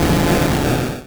Cri de Léviator dans Pokémon Rouge et Bleu.